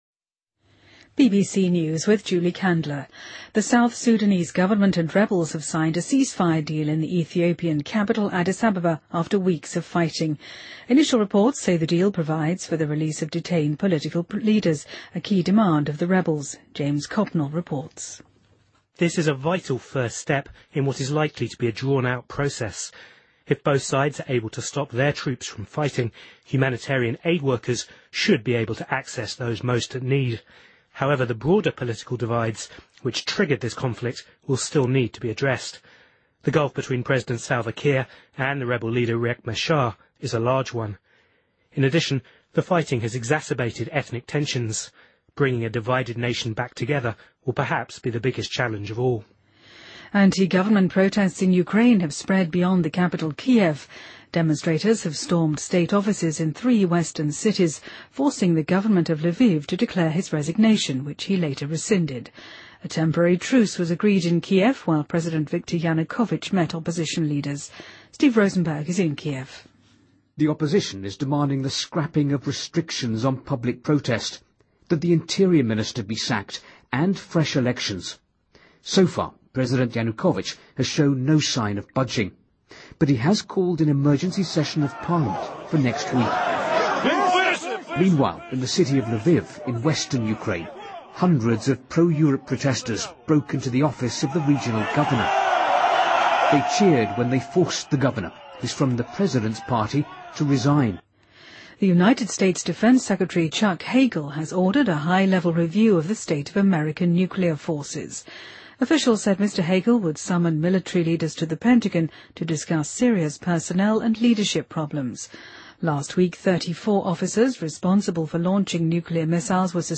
BBC news,2014-01-24